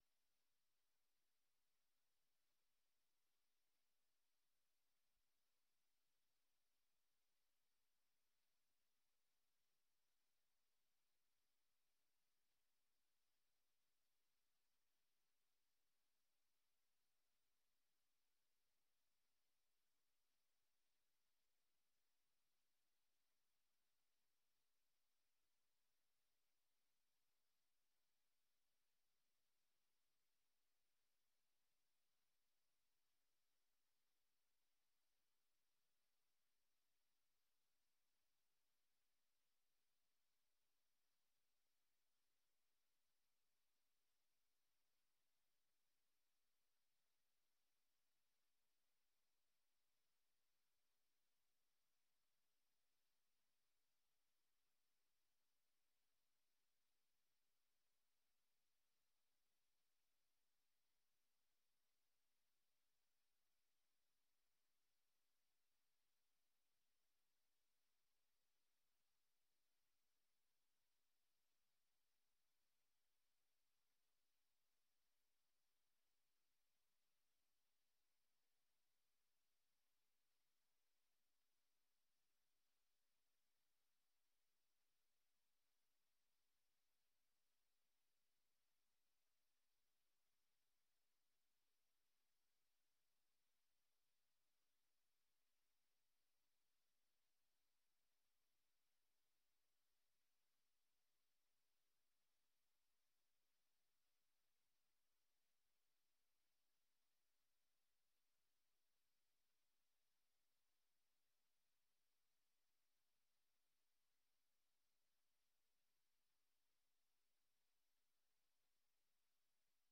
Radio Martí ha reunido a científicos de primer nivel de diferentes partes del mundo: astrónomos de la NASA, biólogos, doctores en Meteorología, expertos en Biodiversidad, entre otros